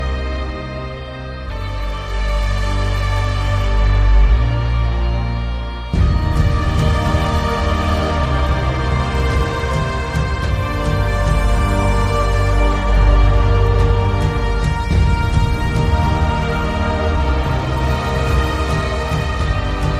# epic # cinematic